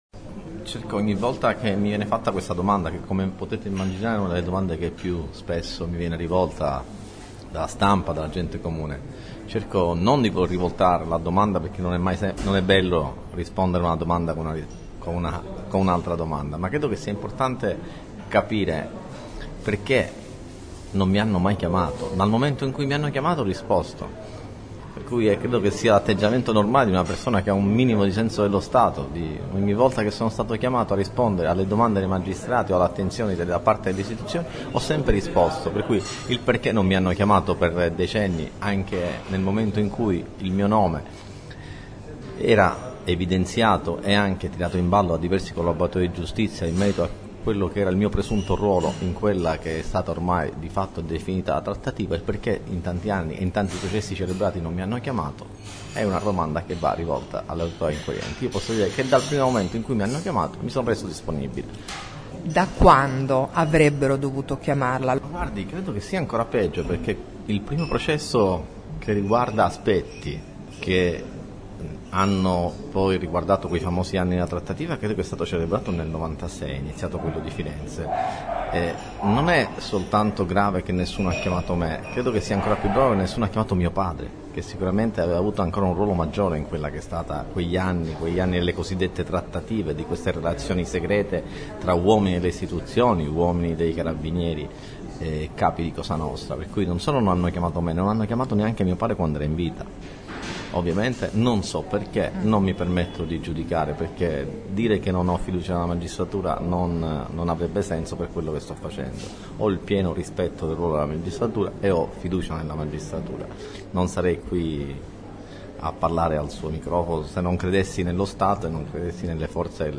“Don Vito”, intervista a Massimo Ciancimino
Ascolta l’intervista di Ciancimino ai microfoni di Città del Capo Radio Metropolitana e di Libera Radio